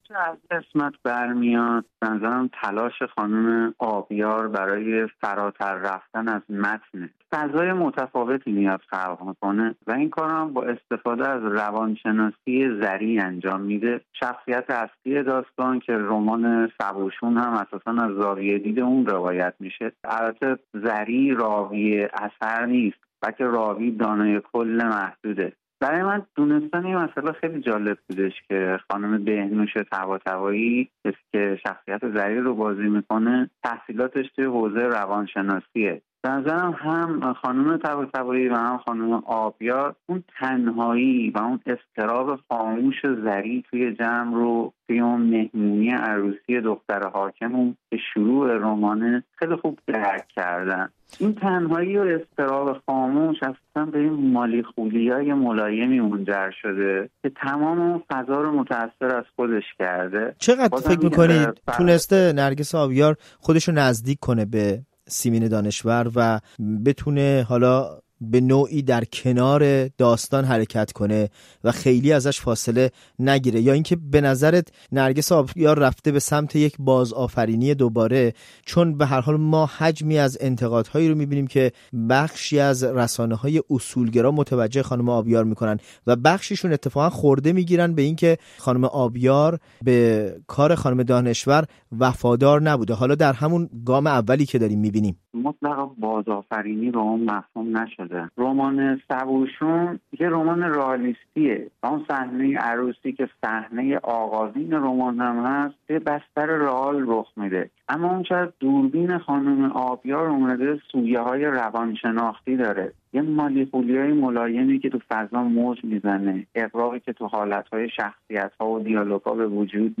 از نگاه یک داستان‌نویس و روزنامه‌نگار